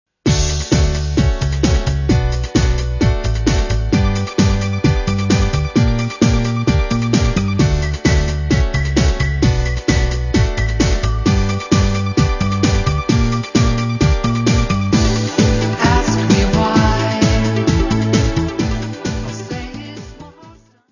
Gravação em stereo
Music Category/Genre:  Pop / Rock